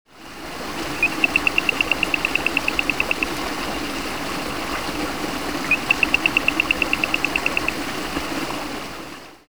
Play Especie: Spinomantis guibei Género: Spinomantis Familia: Mantellidae Órden: Anura Clase: Amphibia Título: The calls of the frogs of Madagascar.
Localidad: Madagascar Tipo de vocalización: Llamada
60 Spinomantis guibei.mp3